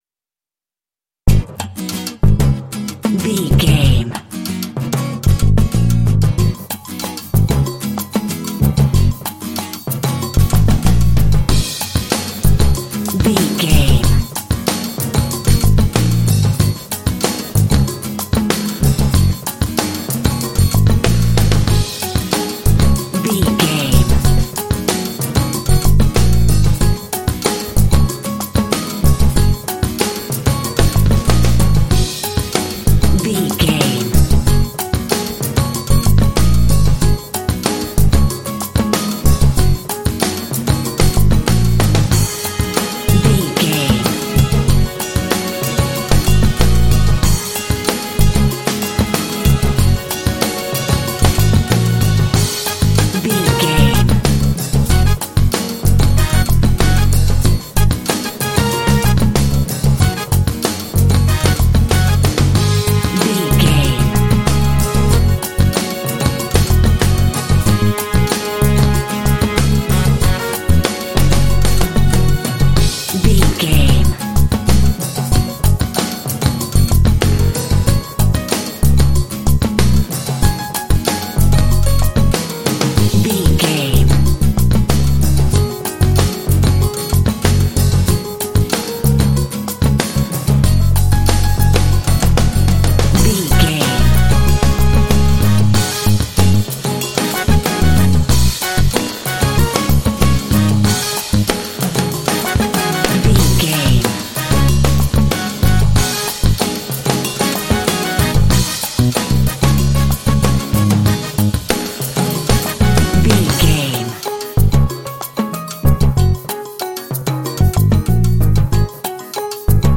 Aeolian/Minor
G#
cool
smooth
bass guitar
drums
acoustic guitar
percussion
piano
strings
trumpet
saxophone
Funk
Lounge
downtempo